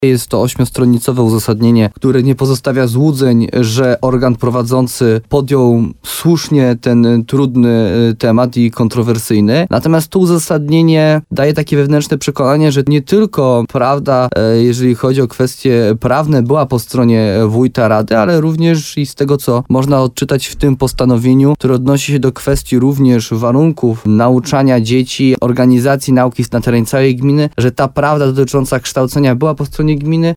– mówił w programie Słowo za słowo na antenie RDN Nowy Sącz Jarosław Baziak, wójt gminy Gródek nad Dunajcem.